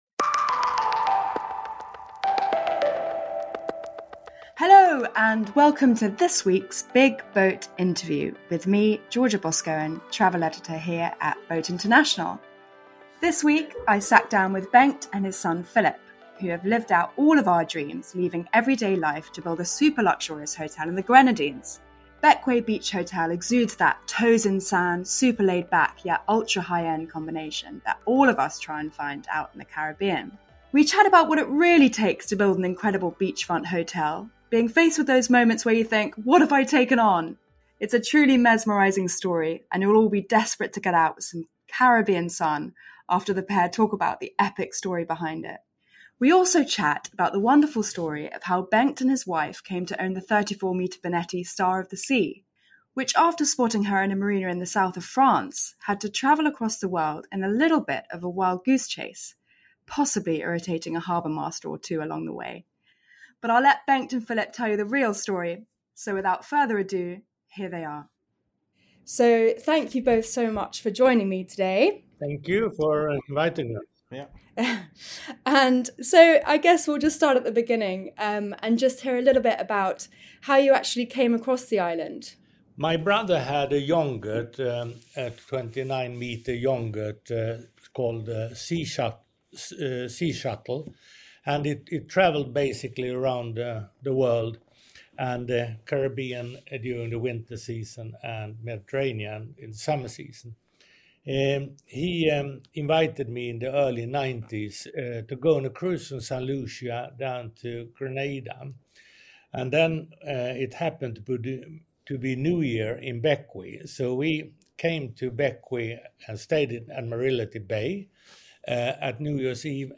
The Big BOAT Interview